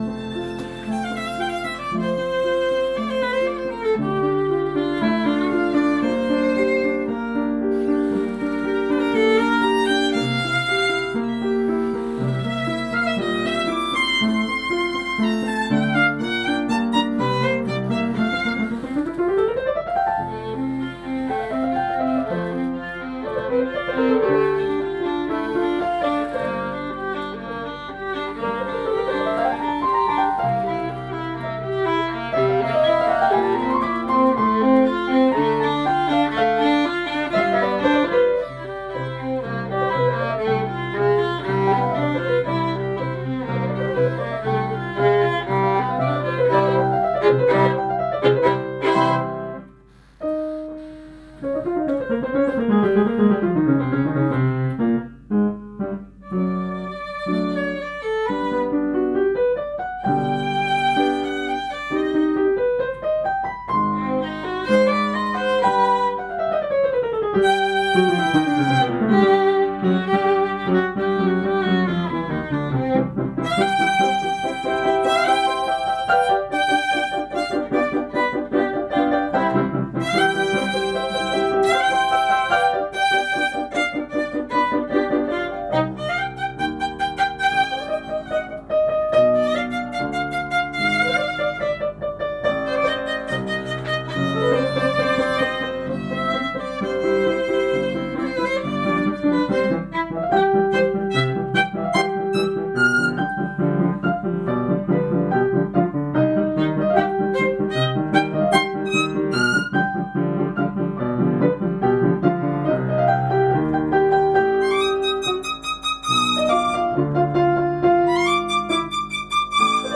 A minidisc recording of a rehearsal of the first movement of Beethoven's Frühling sonata by same duo (converted to .wav):